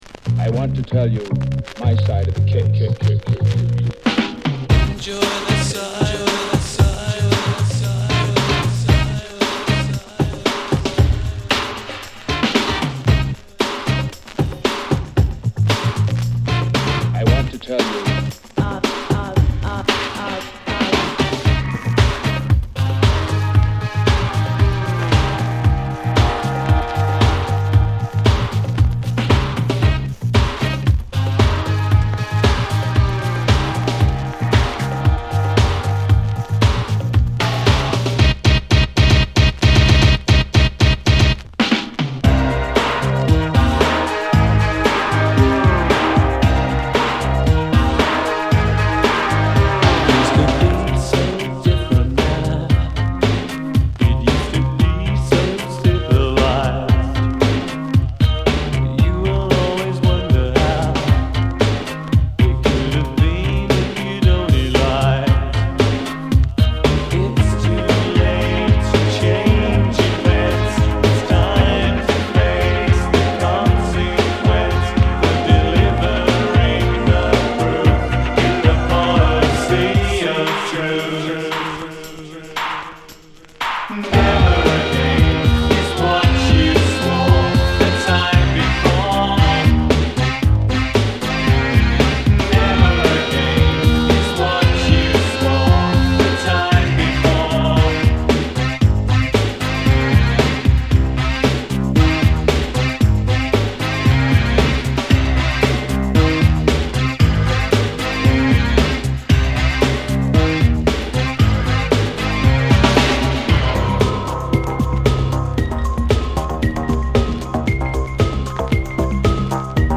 バシバシに強化されたビートも迫力があります。